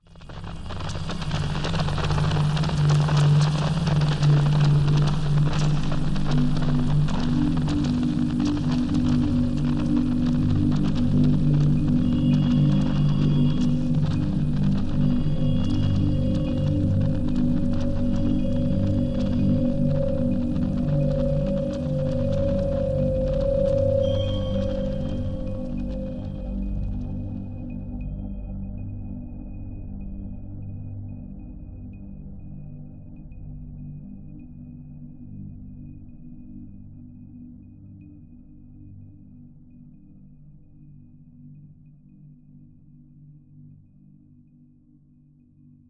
描述：这是个深具质感和温柔的垫子声音。
使用颗粒合成和其他技术创建。
标签： 环境 数字 粒状 多重采样 空间 合成器 纹理
声道立体声